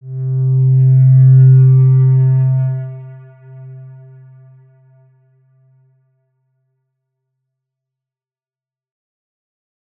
X_Windwistle-C2-ff.wav